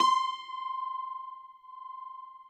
53r-pno18-C4.wav